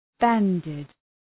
Shkrimi fonetik {‘bændıd}
banded.mp3